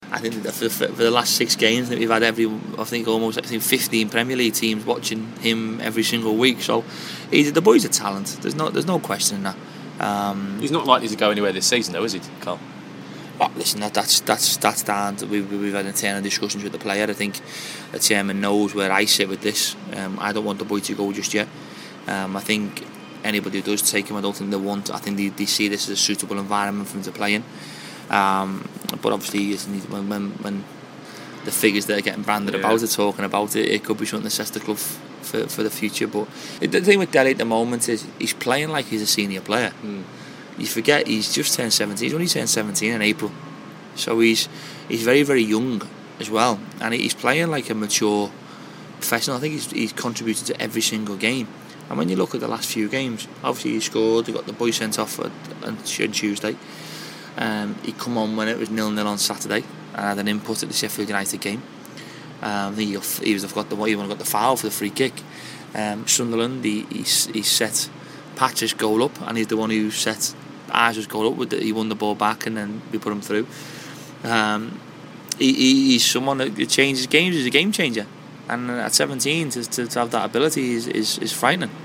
MK Dons manager Karl Robinson talking about Dele Ali.